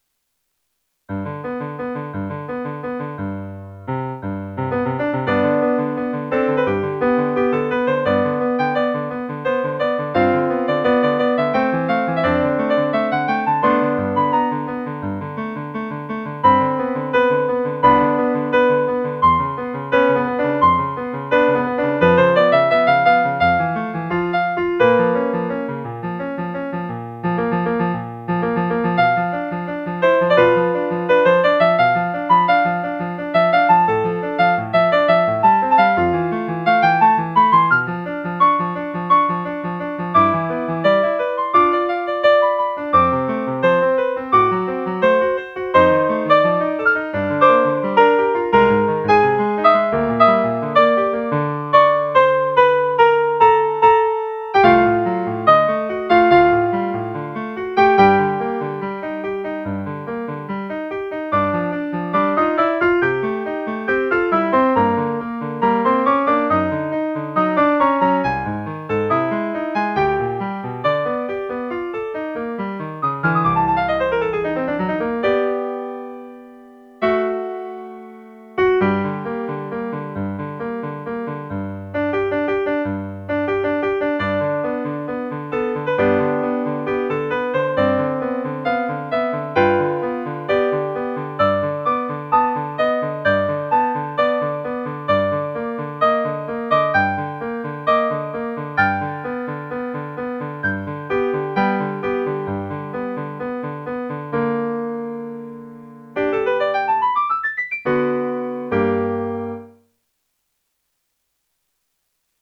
''조안나 그레이'' 중 ''알라스토르의 로망스'' - 피아노 편곡